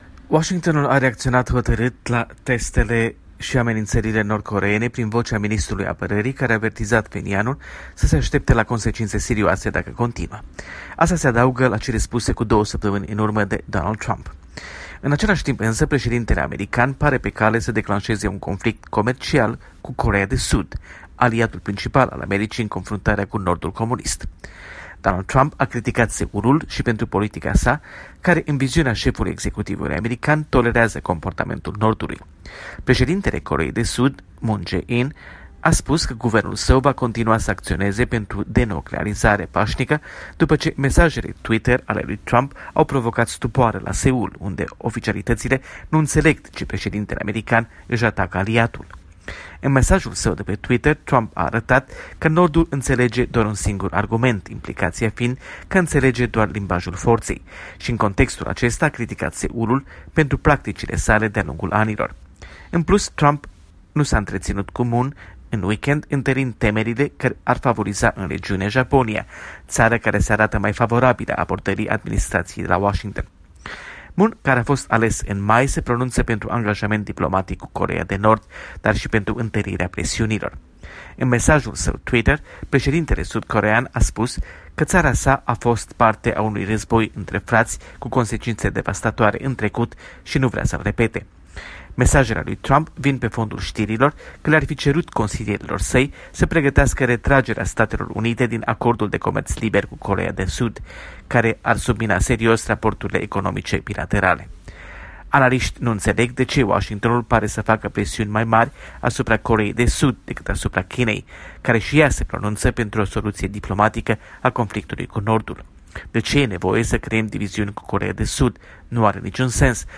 Corespondența zilei de la Washington